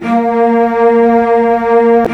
Cellos11_Cellos11-8.wav